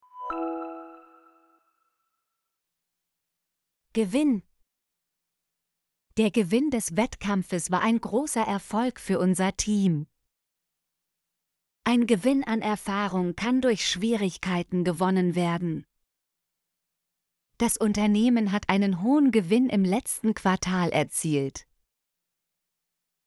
gewinn - Example Sentences & Pronunciation, German Frequency List